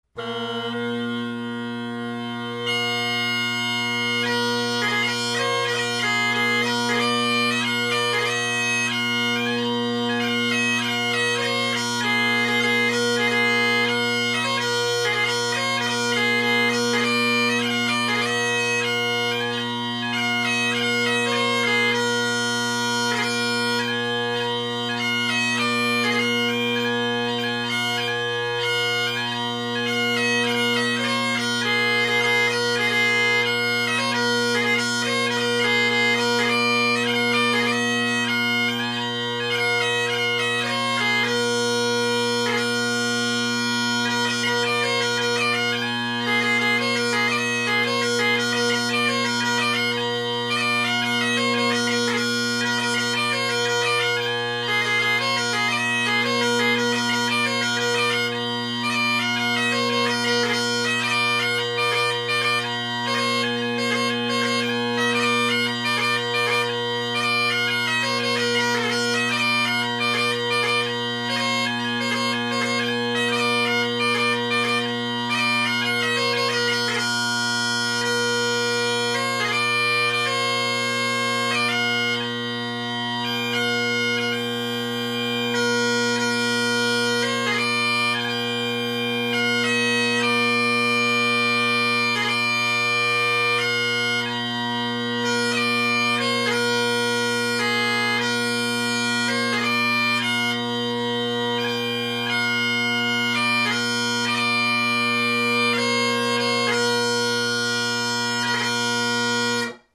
Drone Sounds of the GHB, Great Highland Bagpipe Solo
Here are recordings where the drones are facing the mic.
Battle of Waterloo, A Dram Before you Go, and Deer Forest – Canning with carbon fiber bass